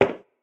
inside-step-3.ogg